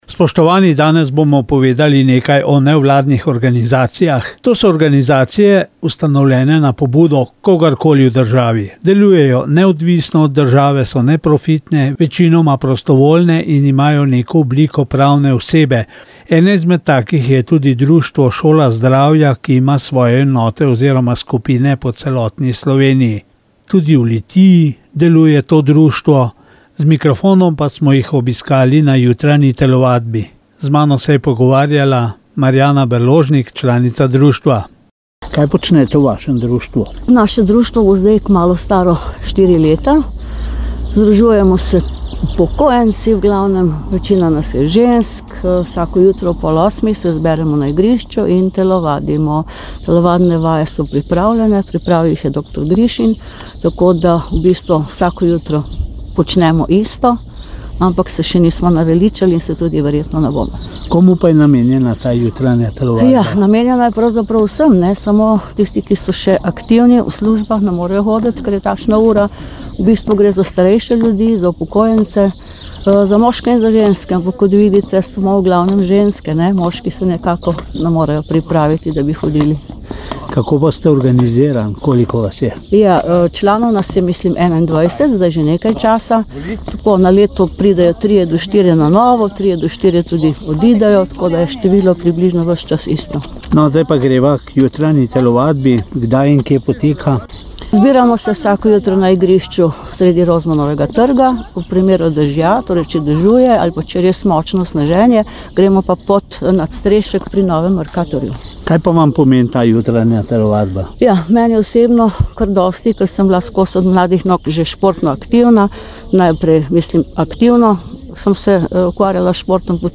Novinar Radia 1 obiskal Društvo Šola zdravja